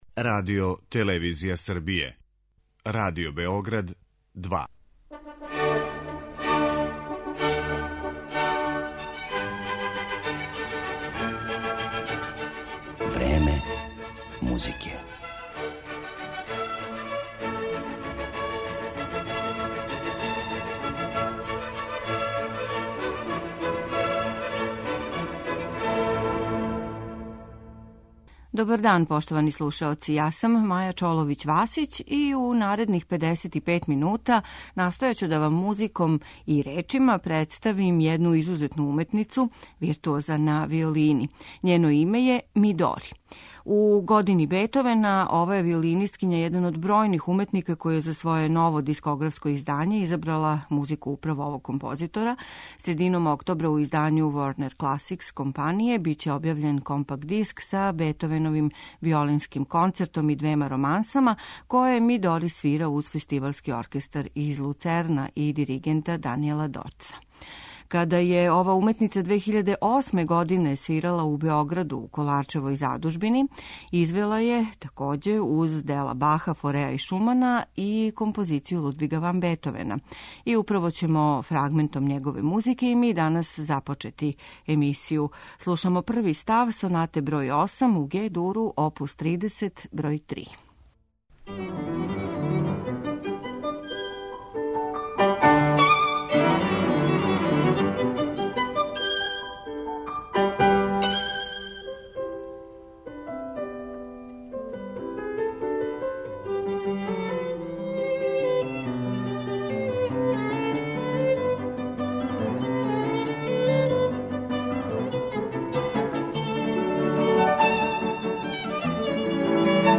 Виолинисткиња Мидори је врхунски извођач, наступа у најпрестижнијим светским дворанама као солиста и камерни музичар.